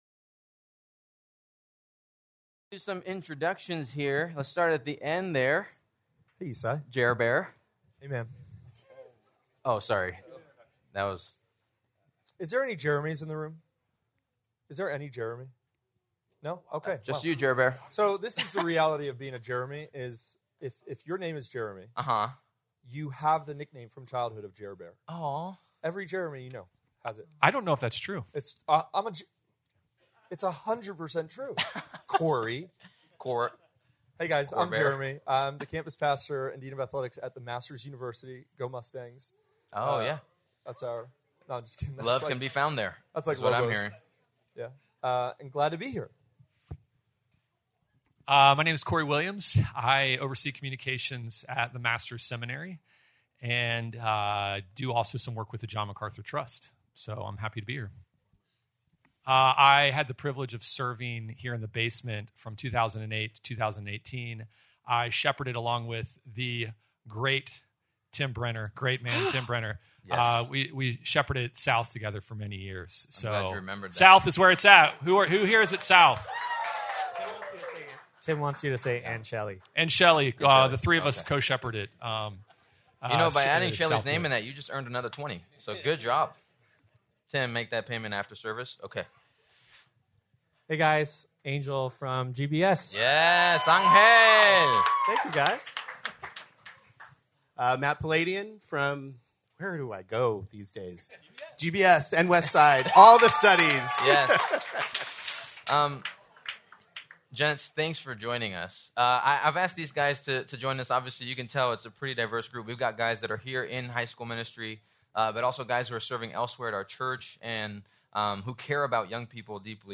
Q&A
Please note that, due to technical difficulties, this recording skips brief portions of audio.